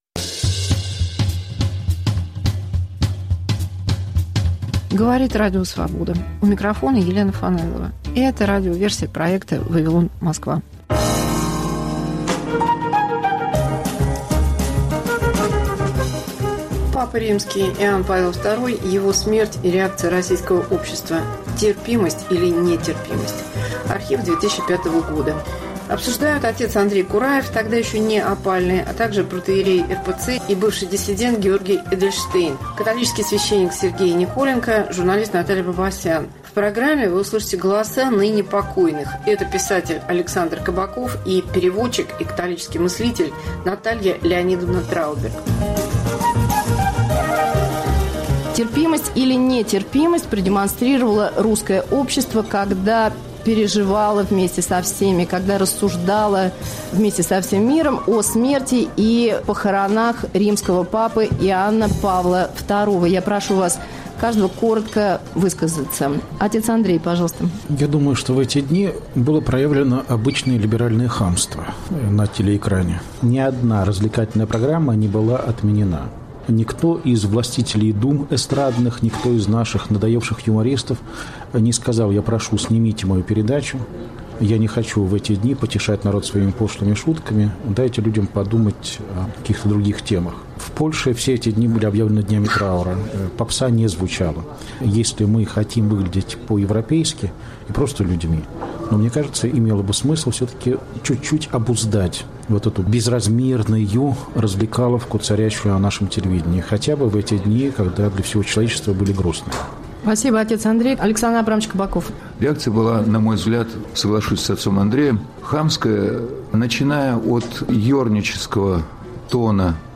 Вавилон Москва. Елена Фанайлова в политическом кабаре эпохи инстаграма. Мегаполис Москва как Радио Вавилон: современный звук, неожиданные сюжеты, разные голоса. 1.